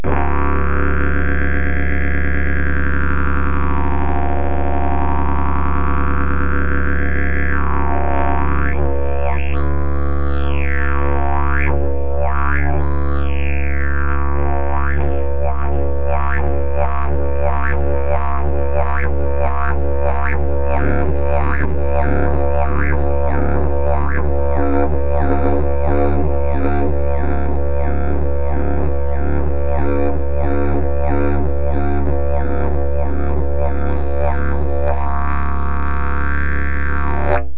Jilm přírodní 2668 - Dřevěné didgeridoo pro začínající, pokročilejší i profesionální hráče.